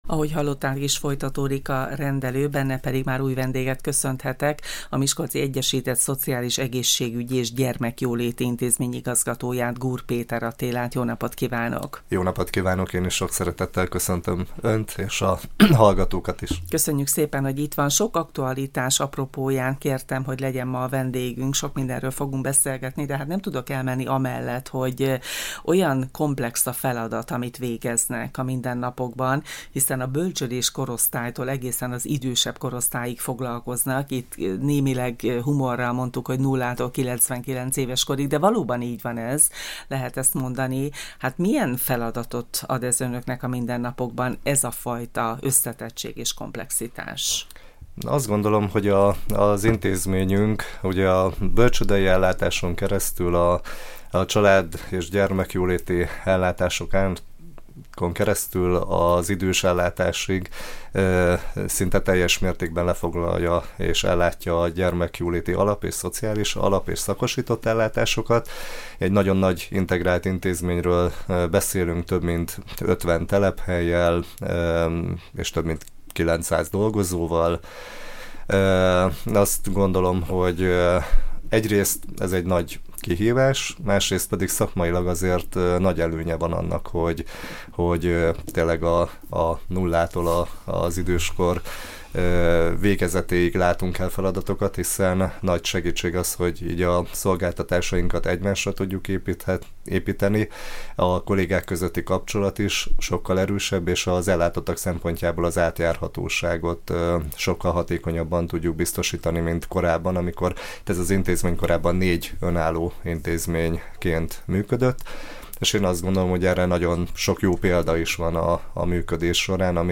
Ebből az apropóból láttuk vendégül stúdiónkban